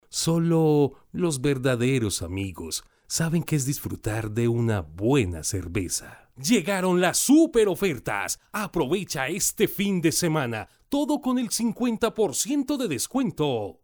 voz promocional, Cálida, cercana, emotiva, institucional
Locutor comercial , promocional, IVR , institucional, documental, E- learning, corporativa
spanisch Südamerika
Sprechprobe: Werbung (Muttersprache):